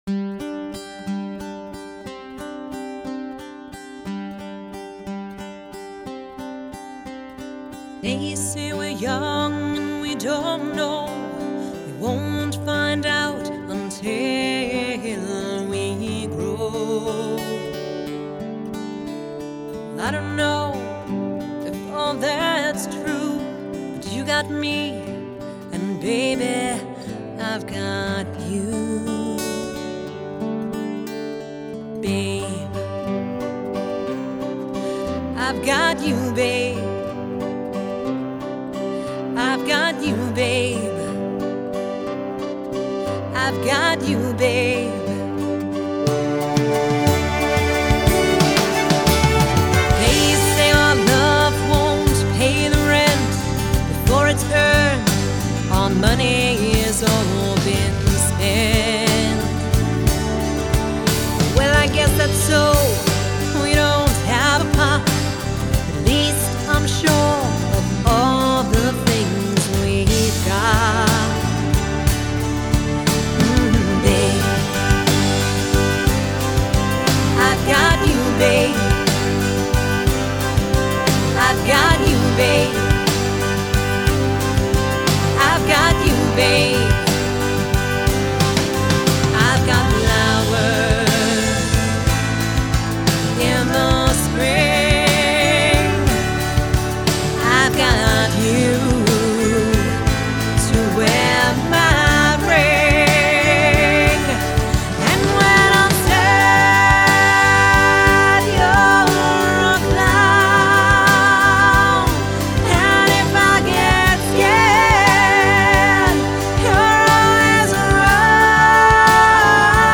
Genre: Folk Rock, Celtic, Medieval